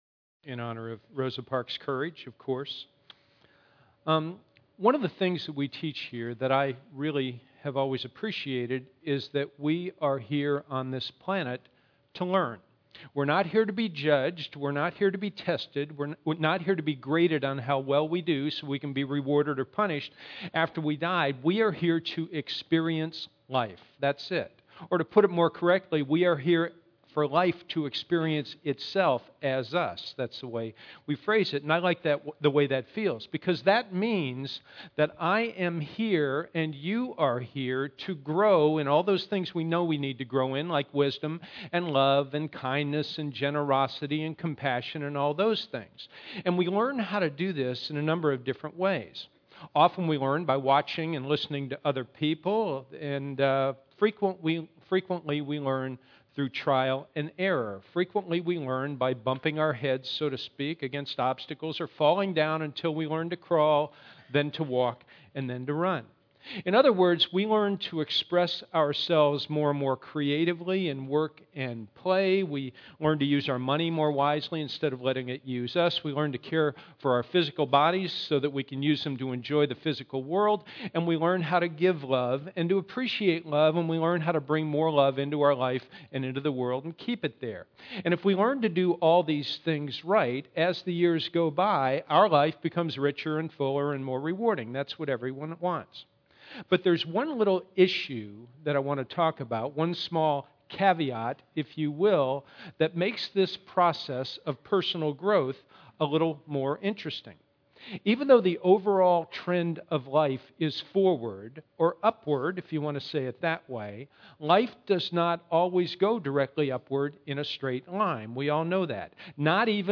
Our one-hour Sunday services are open and comfortable, with music, laughter, and interesting talks.